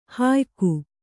♪ hāyku